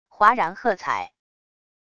哗然喝彩wav音频